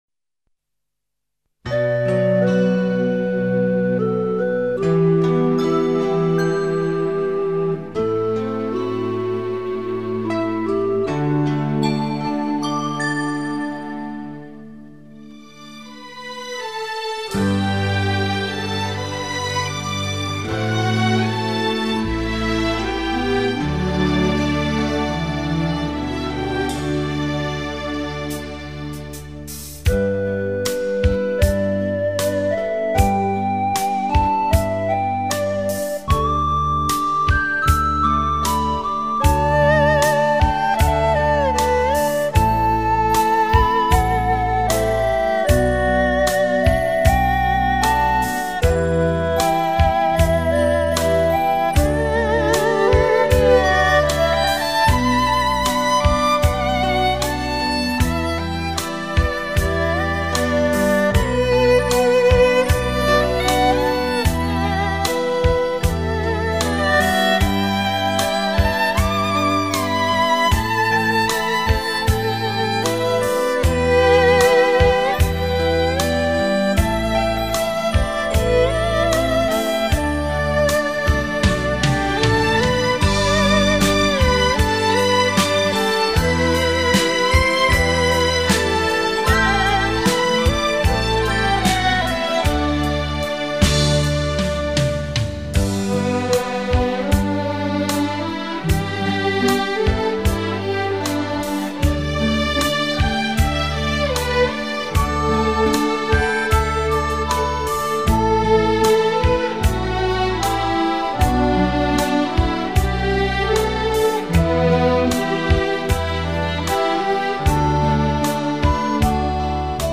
探戈